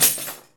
PRC CHIME03L.wav